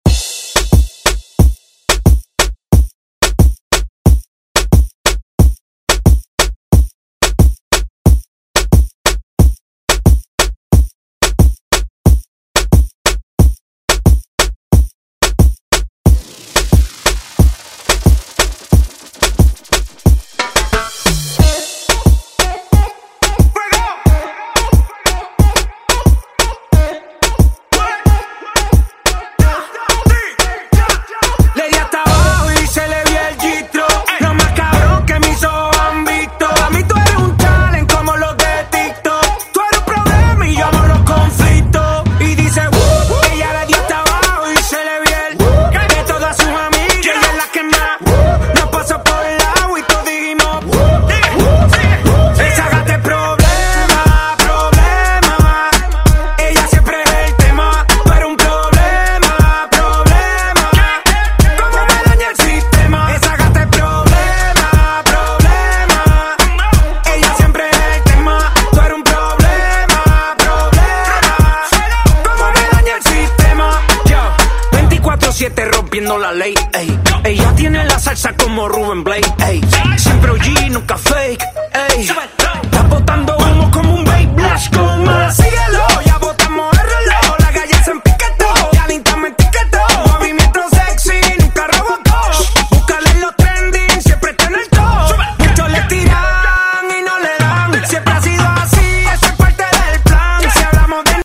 Genres: 90's , DANCE
Clean BPM: 101 Time